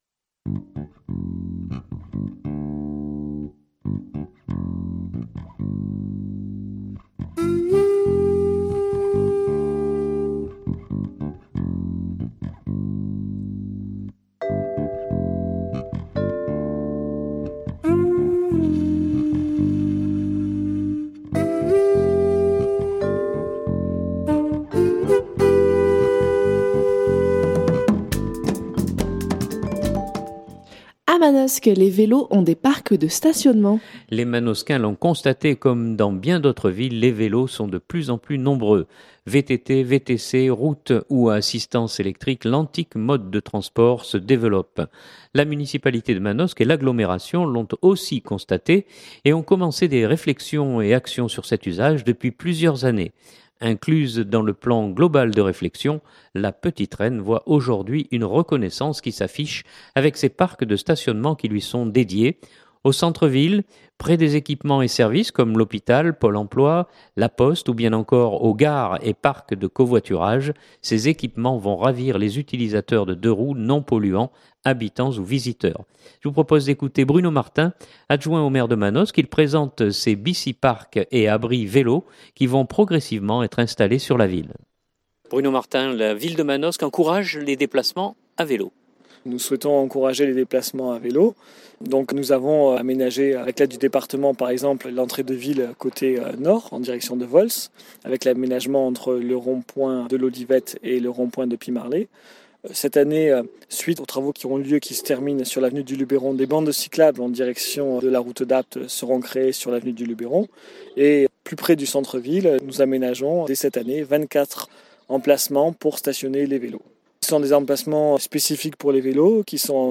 Je vous propose d’écouter Bruno Martin, adjoint au Maire de Manosque. Il présente ces bicyparcs et abris vélos qui vont progressivement être installés sur la ville.